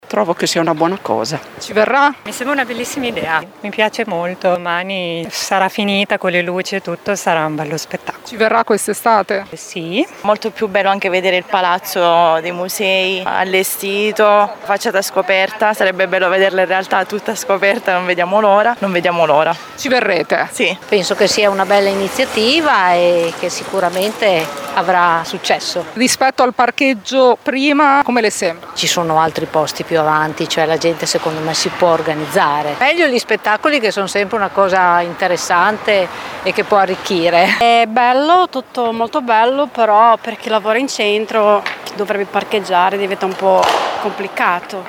Una novità che sembra piacere ai modenesi, qui sotto alcune interviste